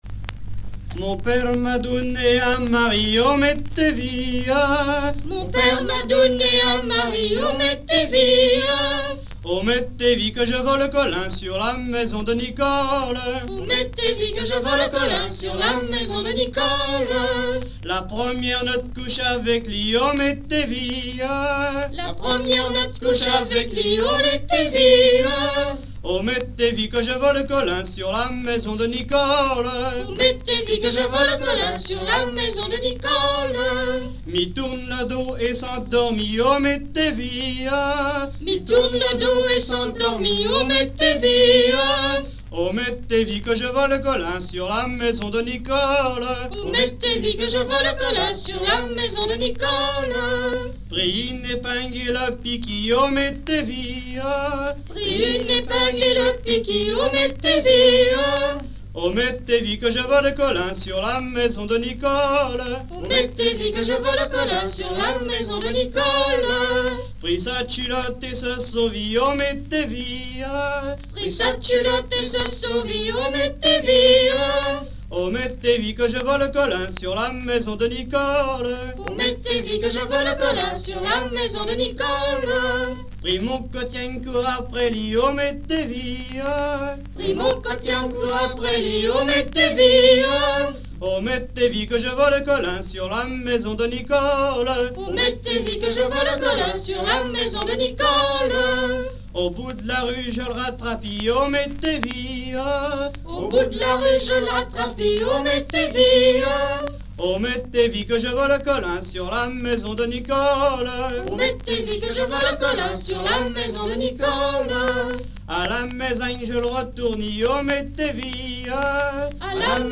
grand'danse
Bois-de-Céné
Genre laisse
Pièce musicale inédite